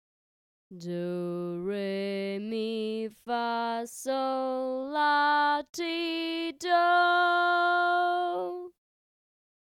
Melody 2 Solfa Scale
Ex-3c-solfa-scale-1.mp3